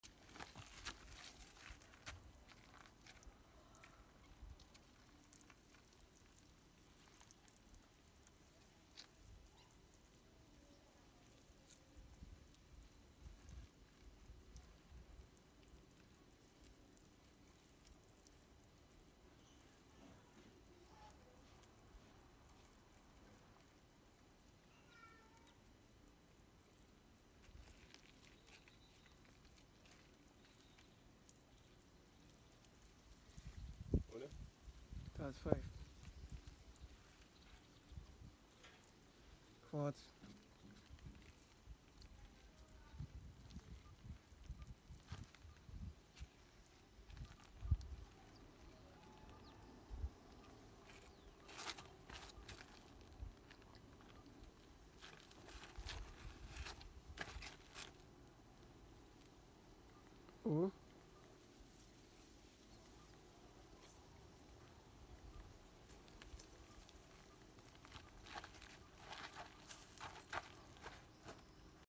Big Engine Sound Effects Free Download